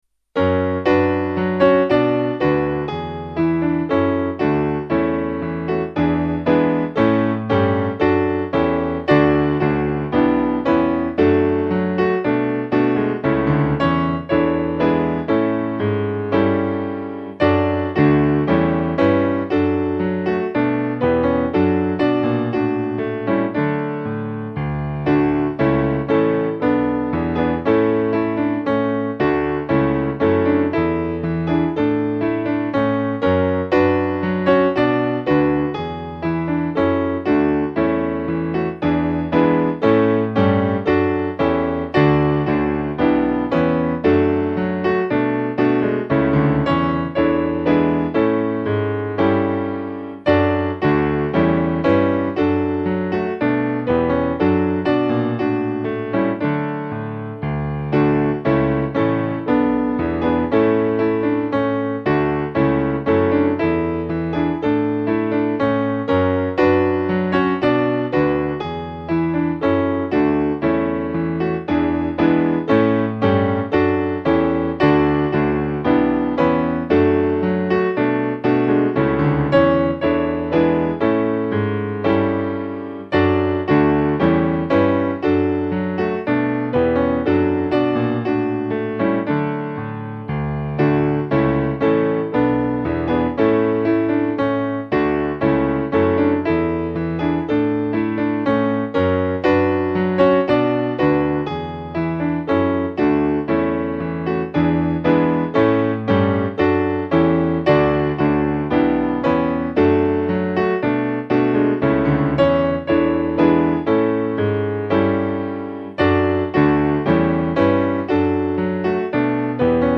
Music by: 17th cent. English melody;